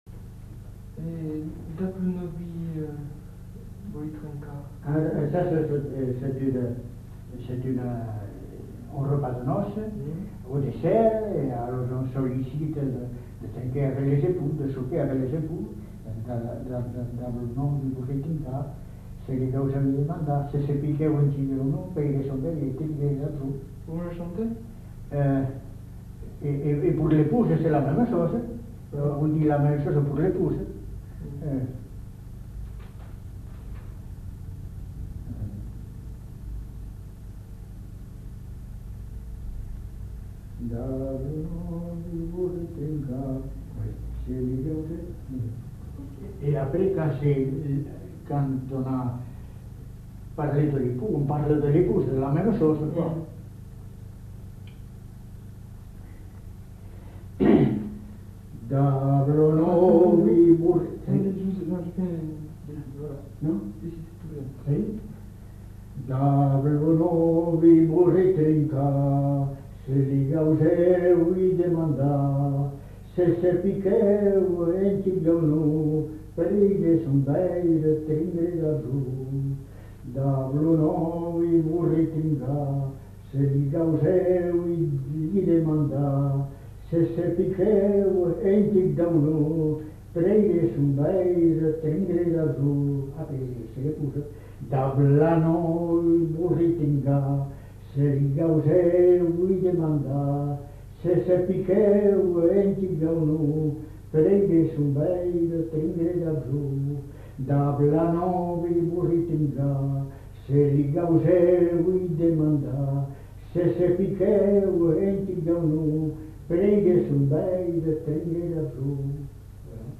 Aire culturelle : Bazadais
Lieu : Bazas
Genre : chant
Effectif : 1
Type de voix : voix d'homme
Production du son : chanté